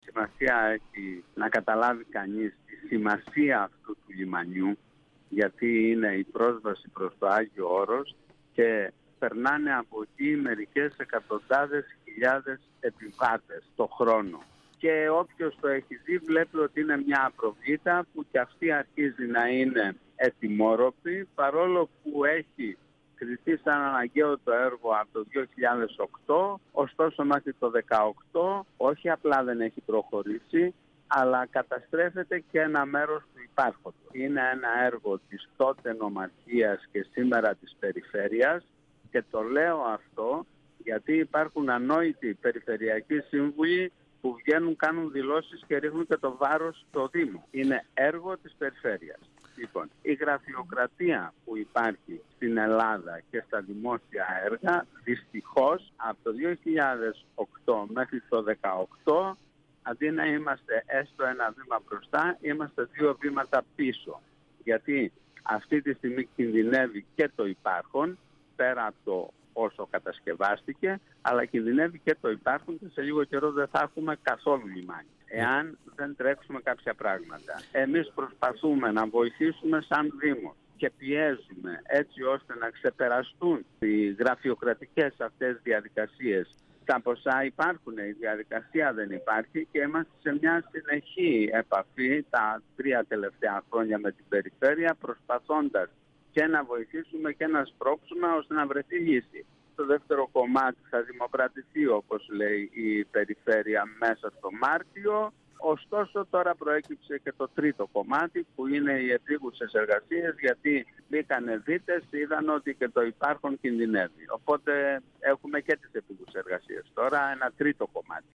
Ο δήμαρχος Αριστοτέλη, Γιώργος Ζουμπάς, στον 102FM του Ρ.Σ.Μ. της ΕΡΤ3
Συνέντευξη